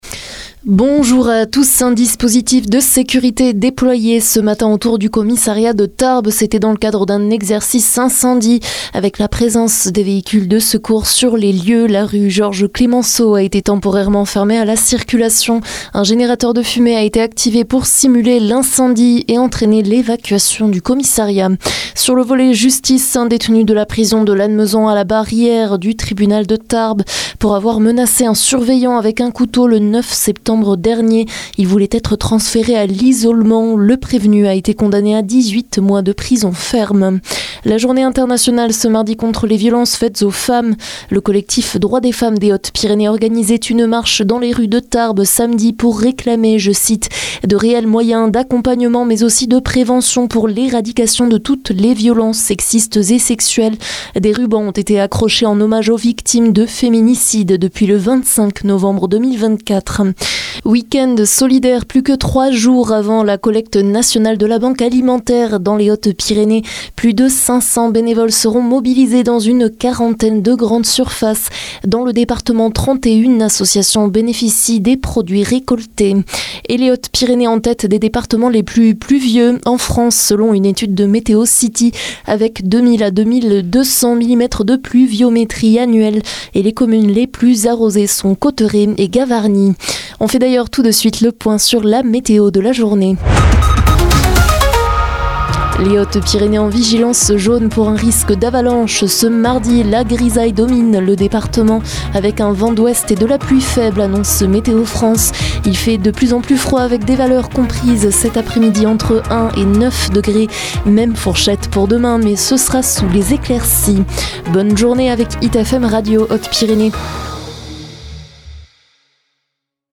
Flash HPY 25 novembre midi